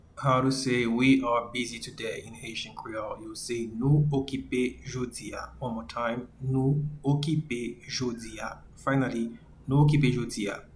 Pronunciation:
We-are-busy-today-in-Haitian-Creole-Nou-okipe-jodi-a.mp3